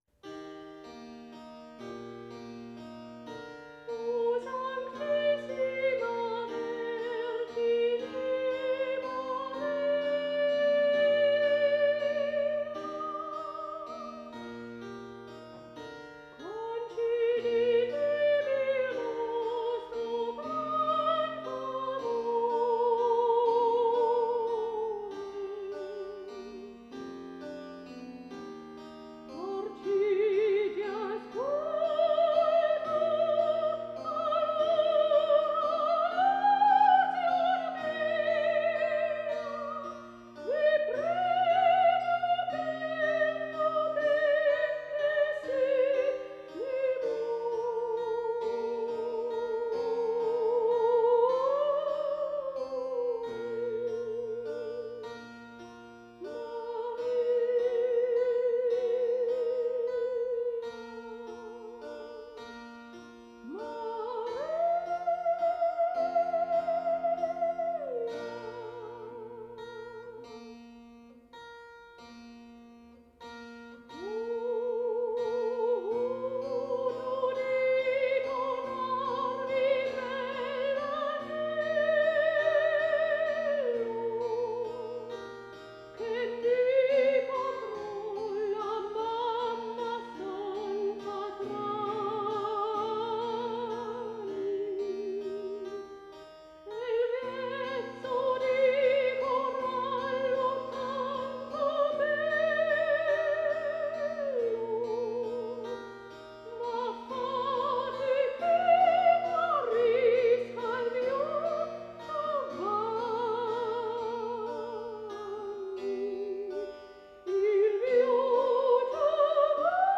O sanctissima Vergine Maria Cembalo